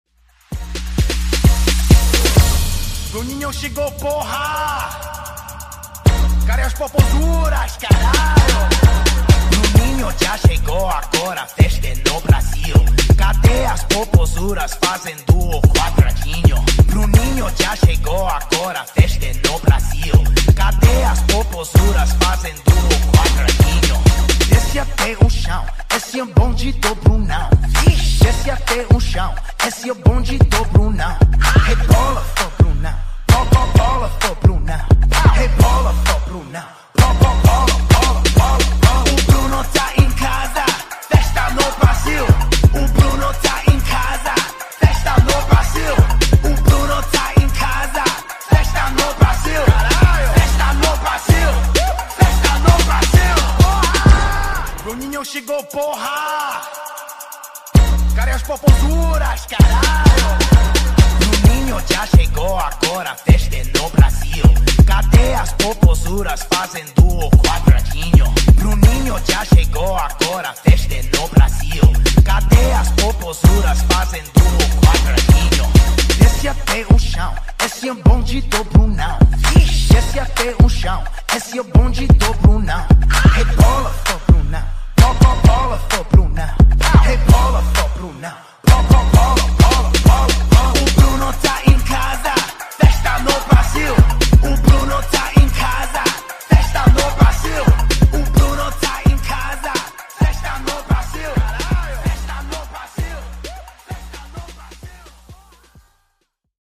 Genres: DANCE , EDM , RE-DRUM
Clean BPM: 128 Time